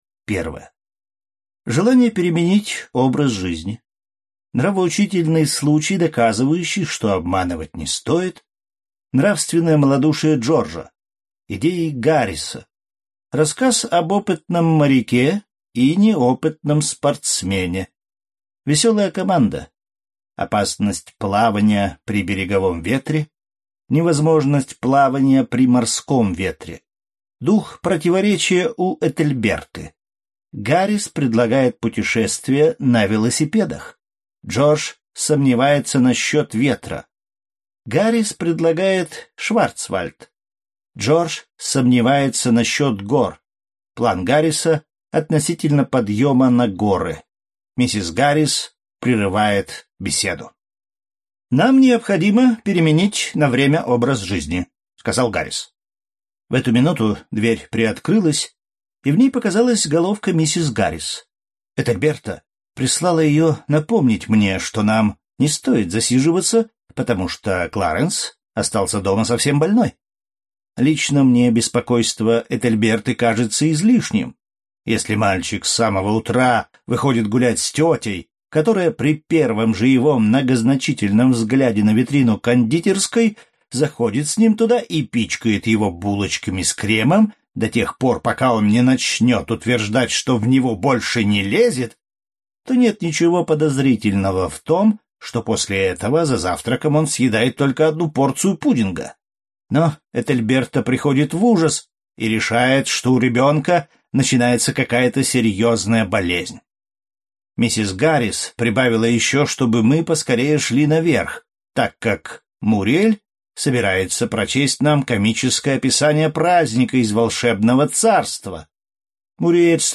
Аудиокнига Трое на четырех колесах | Библиотека аудиокниг